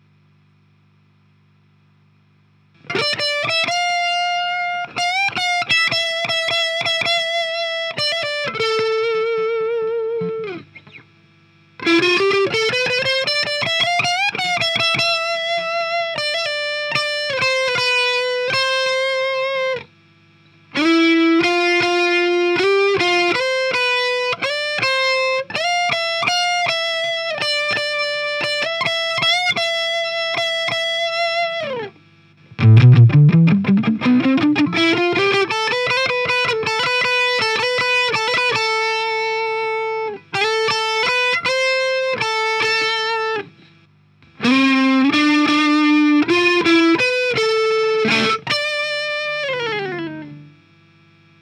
Ca c'est la piste réamp :
Dans mon exemple perso, sur le plan que j'ai mis, je voulais jouer avec une faible attaque de médiator, en retenue, volume de guitare légèrement baissé, un peu hésitant, micro manche pour contraster avec ce qu'il y a avant et après.
J'ai amplifié ça avec le pan léger (40/50%) et le delay inversé suivant les plans. La guitare à droite a le premier delay à gauche et inversement.
REAMP_HB2_02.wav